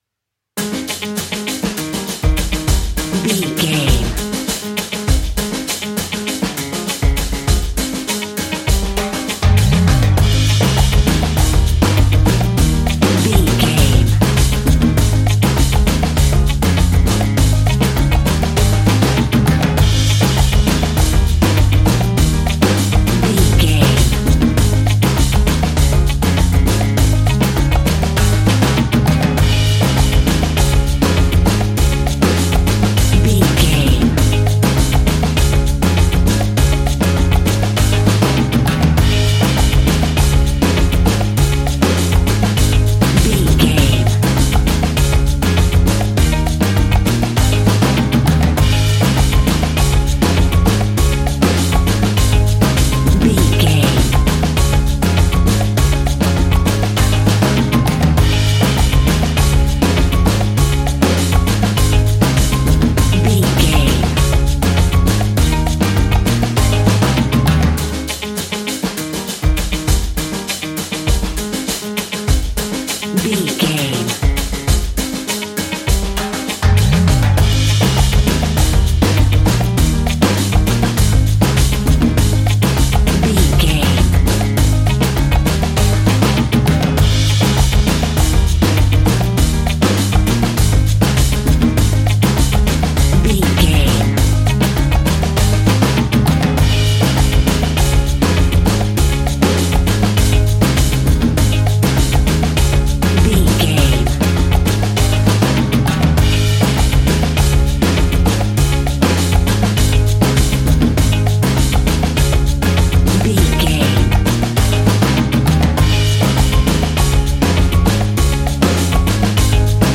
Ionian/Major
A♭
steelpan
drums
percussion
bass
brass
guitar